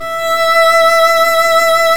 Index of /90_sSampleCDs/Roland L-CD702/VOL-1/STR_Violin 1 vb/STR_Vln1 _ marc
STR VLN MT0I.wav